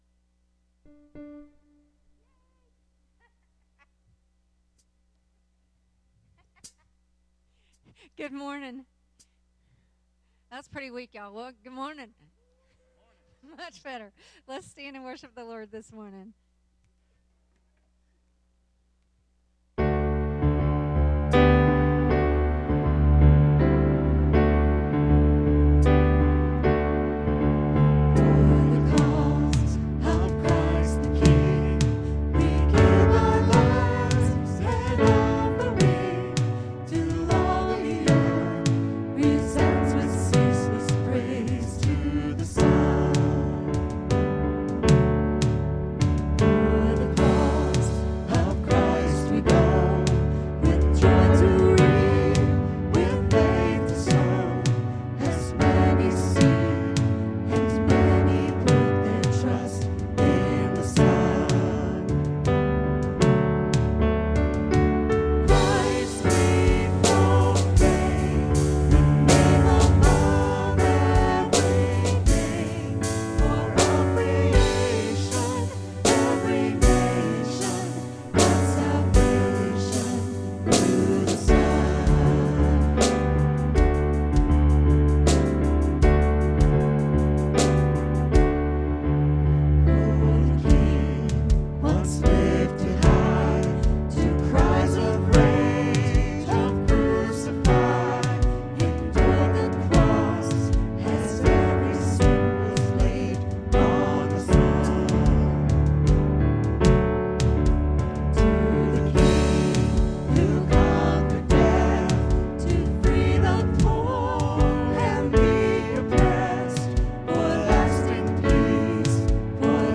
HillSong Church Sermons